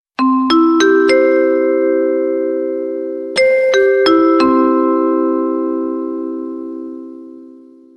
Airport message alert tone ringtone free download
Message Tones